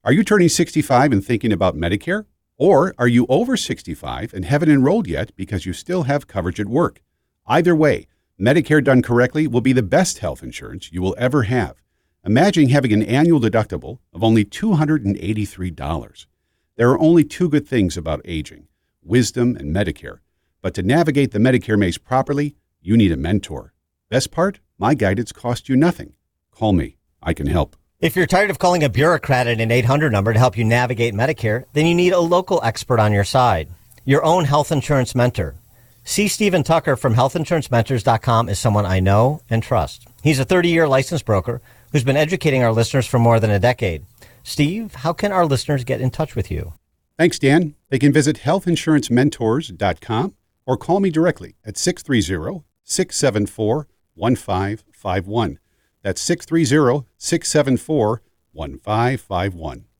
2026-Medicare-radio-ad-Health-Insurance-Mentors.mp3